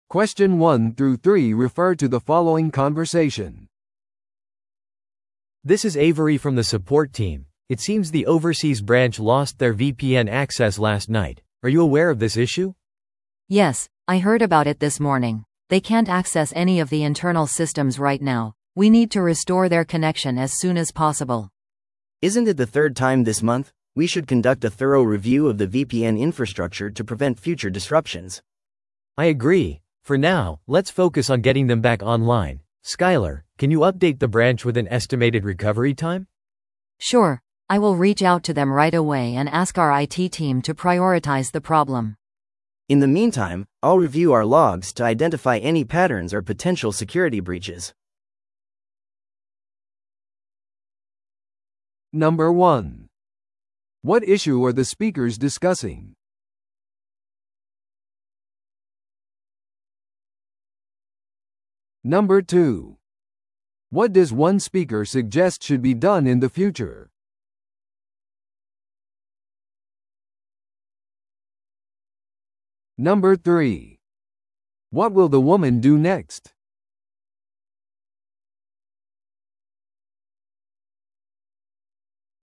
TOEICⓇ対策 Part 3｜海外支店のVPN接続障害への対応 – 音声付き No.247
No.3. What will the woman do next?